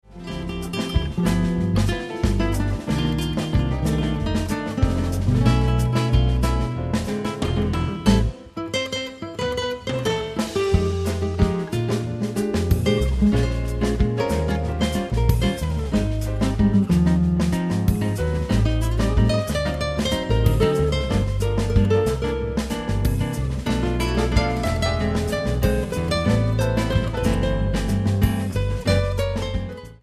Genre: Jazz
drums, percussion
woodwinds